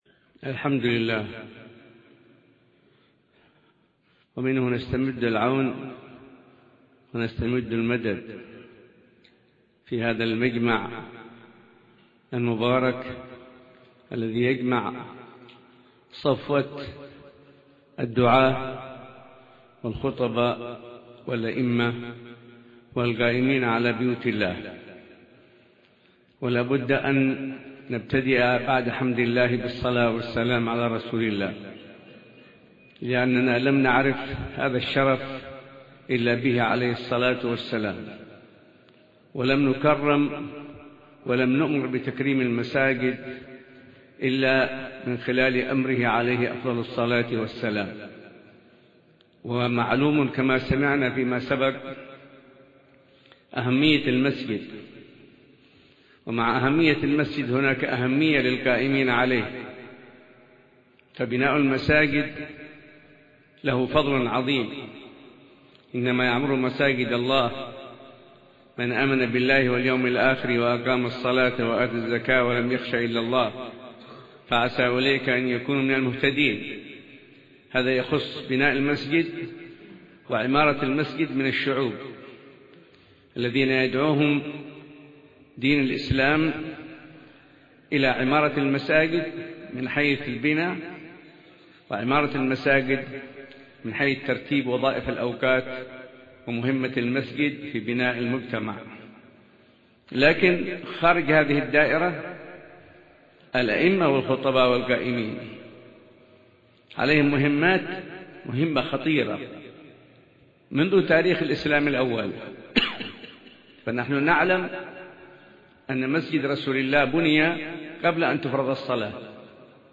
كلمة
في الحفل التكريمي للأئمة والخطباء والعاملين في بيوت الله